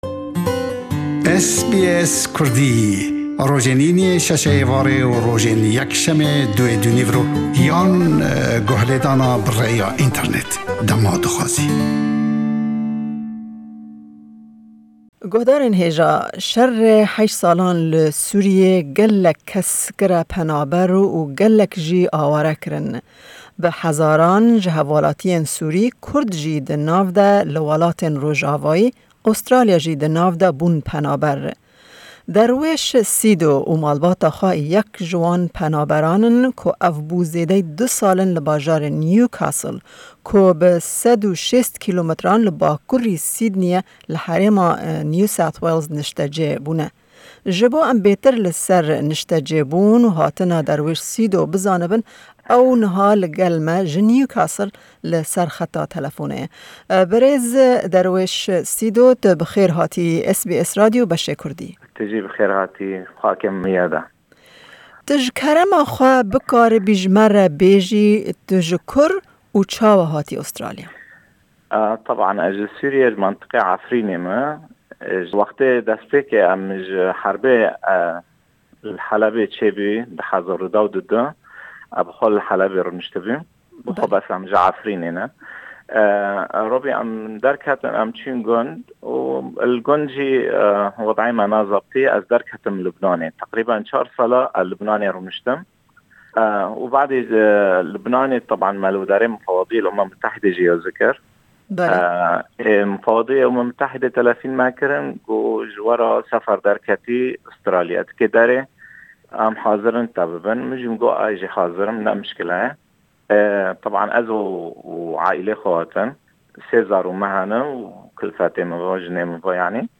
Me hevpeyvînek derbarê niştecêbûn